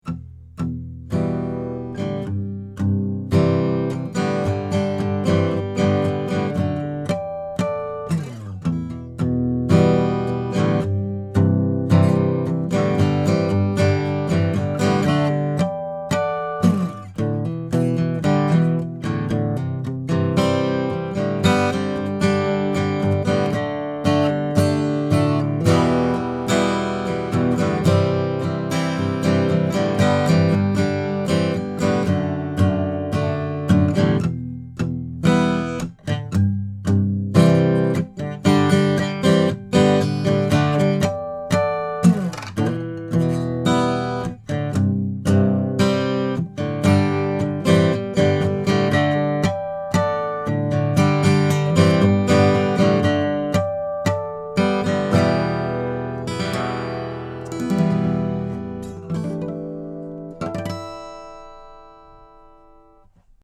Here are some quick, 1-take MP3 sound files showing how each pattern sounds through a Presonus ADL 600 preamp into a Rosetta 200 A/D converter.
Santa Cruz OM/PW Guitar
CARDIOID